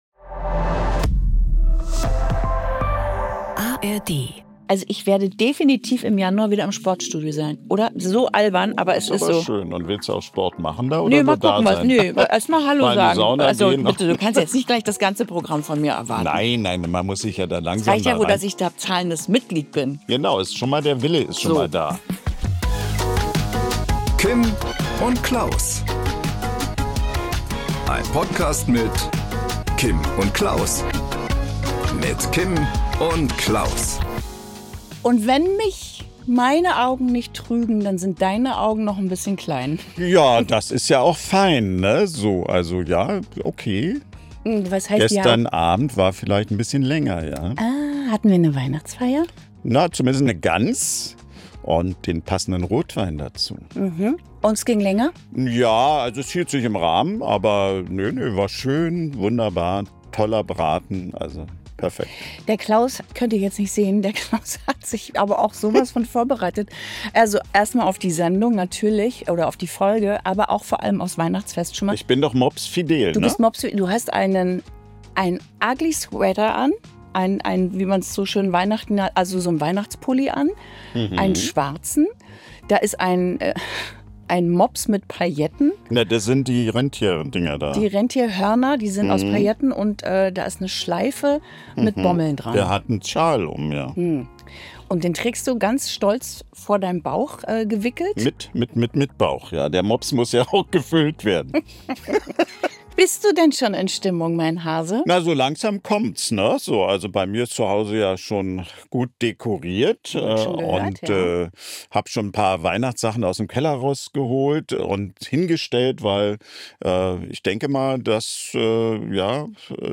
Klaus im glitzernden Mops-Pulli, Kim mit Wunderkerzen-Panik – zusammen plaudern sie über schräge Geschenkideen, Weihnachtsstress und Klaus’ legendäres Festmahl.
Credits: „Kim & Klaus“ Mit Hosts Kim Fisher und Klaus Wowereit Idee: Kim Fisher Ein Podcast von rbb 88.8, produziert von FLOW media company GmbH.